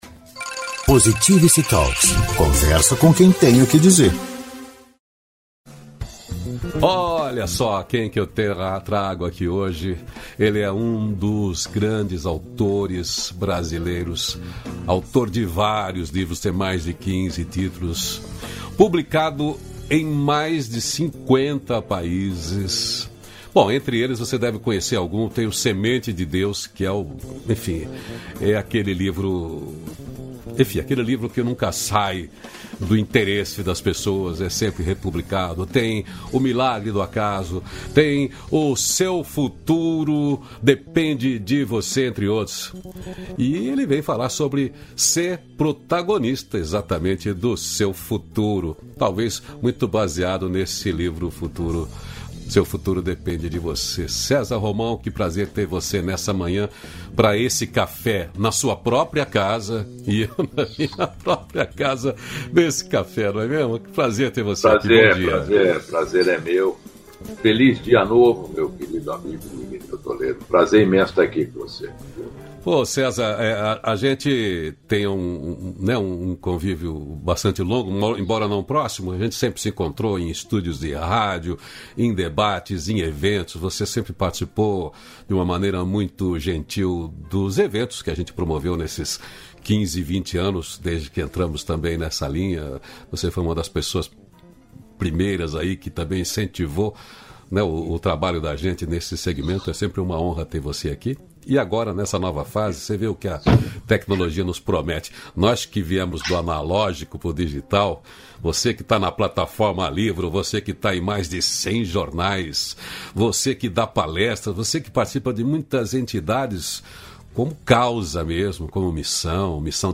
275-feliz-dia-novo-entrevista.mp3